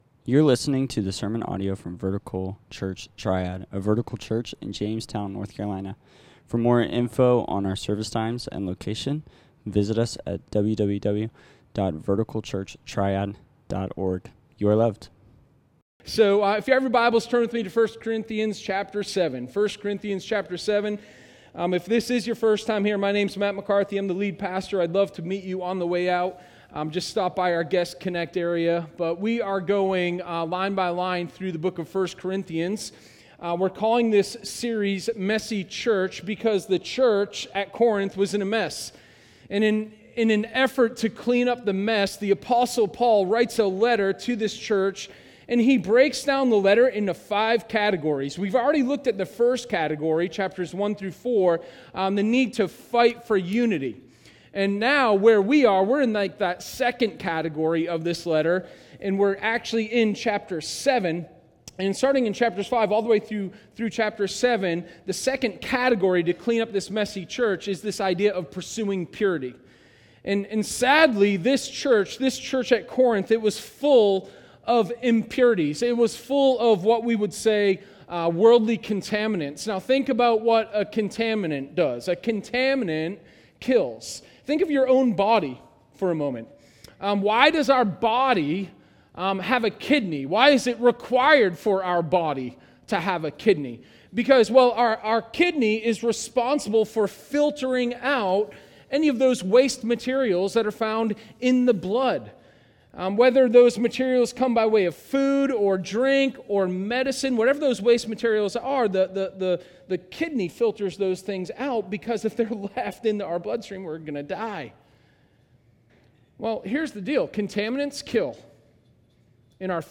Sermon02_06_Sexual_Intimacy_for_Gods_Glory.m4a